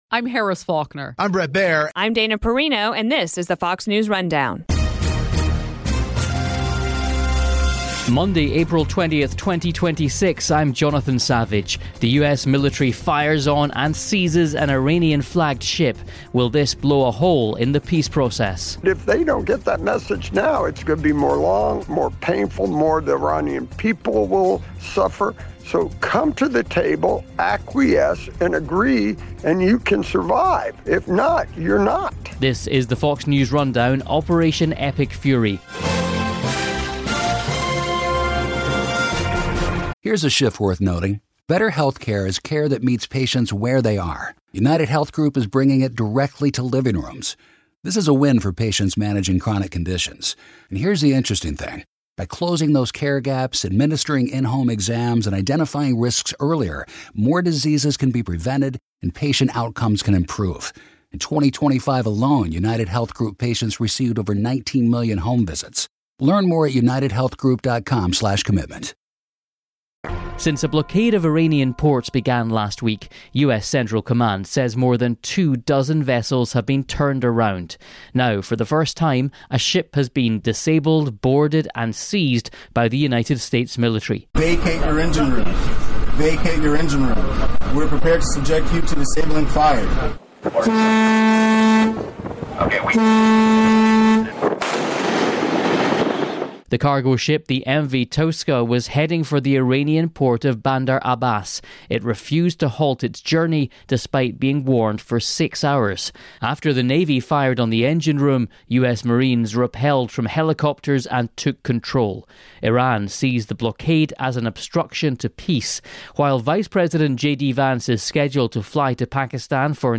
U.S. Navy Seizes Iranian Tanker, More Talks In Doubt: VADM (ret.) Harward on Fox News Radio
speaks with Retired Vice Admiral Robert Harward, JINSA Iran Policy Project Advisor and Former U.S. CENTCOM Deputy Commander